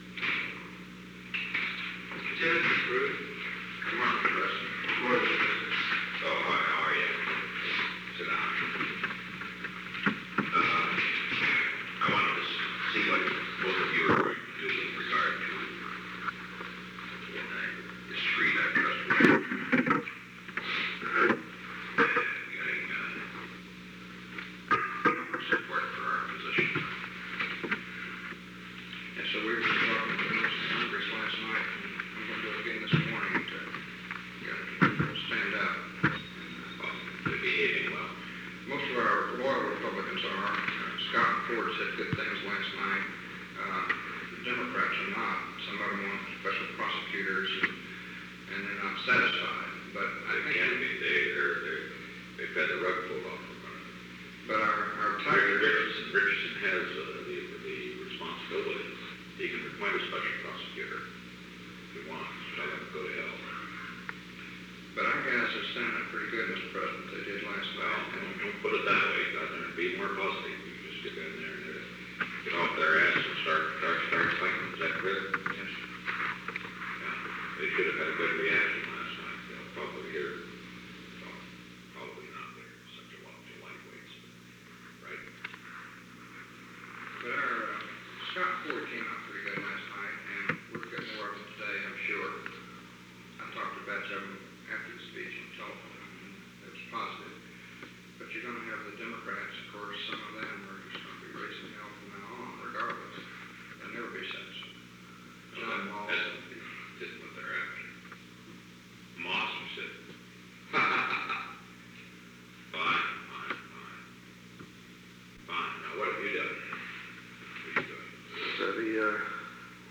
Secret White House Tapes
Conversation No. 908-3
Location: Oval Office
The President met with William E. Timmons and William J. Baroody, Jr.